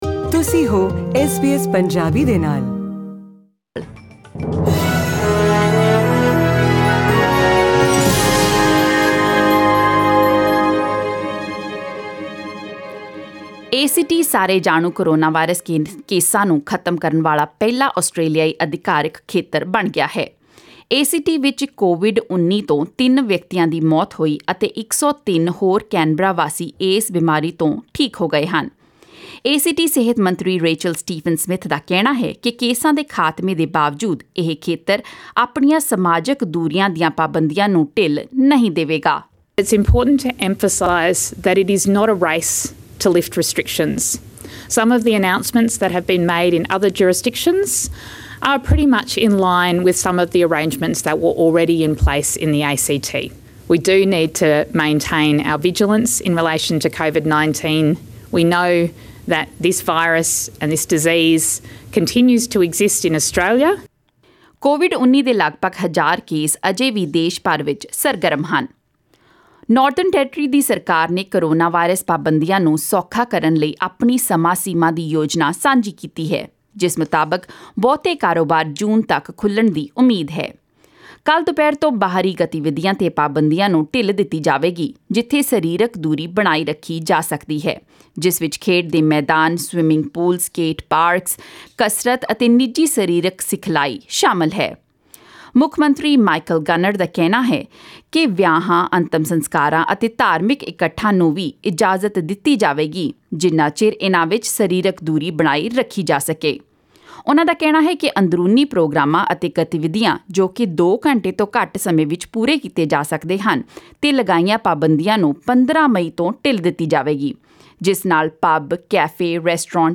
Australian News in Punjabi: 30 April 2020